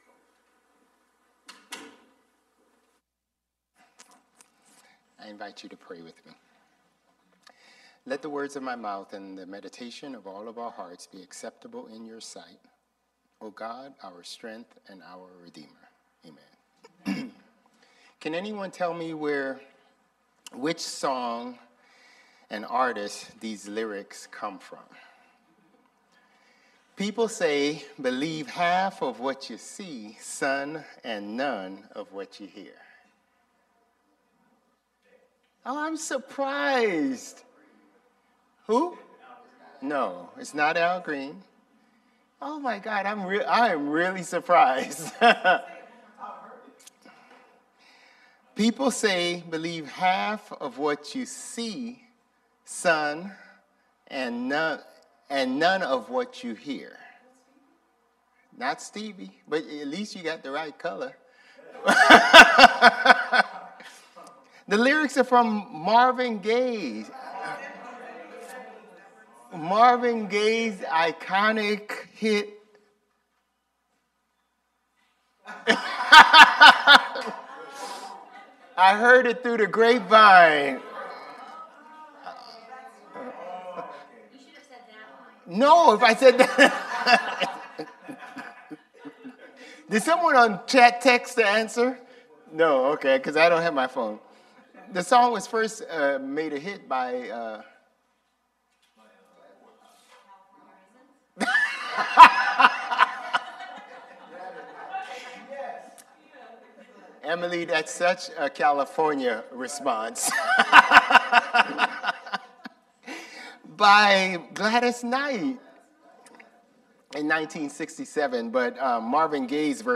Sermons | Bethel Lutheran Church
January 11 Worship